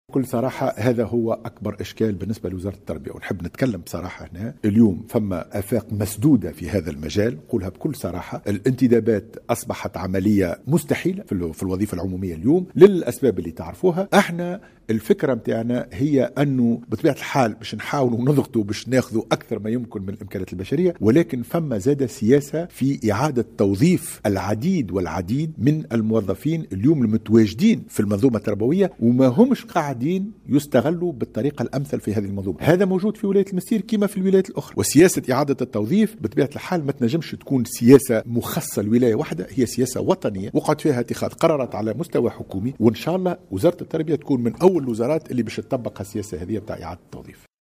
وبيّن بن سالم، في تصريح لمراسل الجوْهرة أف أم، لدى إشرافه اليوْم الجمعة، بالمنستير على مجلس جهوي للتربية، أن وزارته ستكون أولى الوزارات التي ستبادر إلى اعتماد سياسة إعادة التوظيف التي تم إقرارها على مستوى حكومي، مشيرا إلى أن وزارة التربية ستحاول بالتوازي مع ذلك، الضغط لمزيد تدعيم مواردها البشرية.